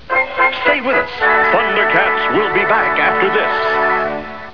Announcer: "Stay with us, ThunderCats will be back!" (Commercial theme) (.wav)